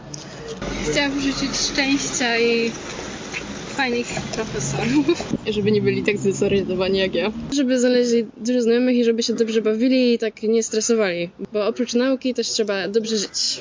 Zapytaliśmy także o to czego chcieliby życzyć innym studentom: